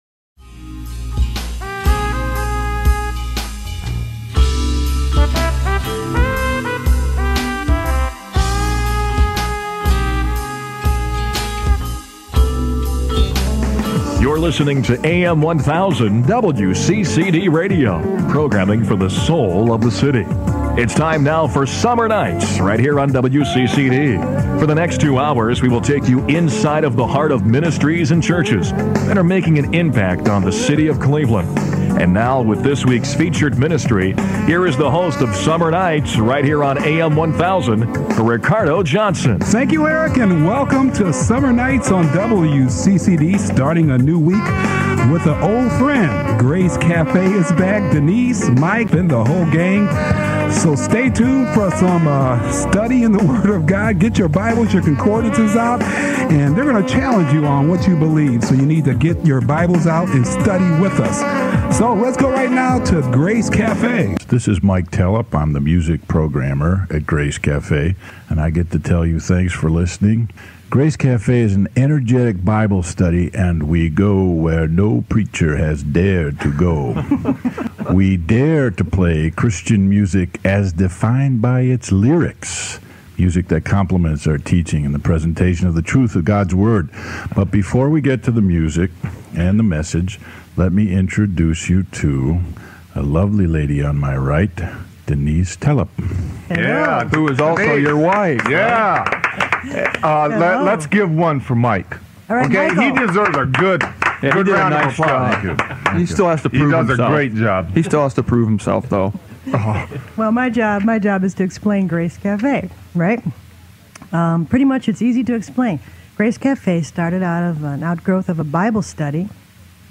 It's live.
We were going to set the record straight on the false teaching of eternal torment, addressing every possible objection and heralding every verse that positively stated the truth of the salvation of all. It was sweaty radio, that's for sure.